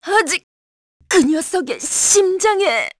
Seria-Vox_Dead_kr.wav